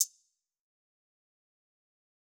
DAHI HIHAT.wav